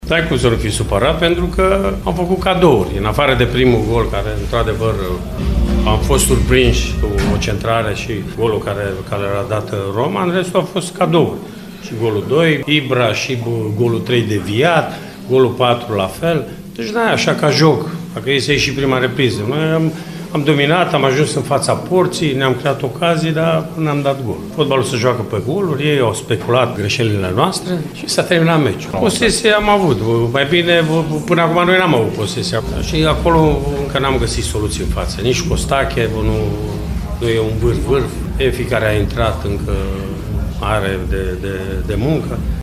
La final, antrenorul Mircea Rednic a vorbit despre trei cadouri din totalul de patru goluri încasate de echipa sa: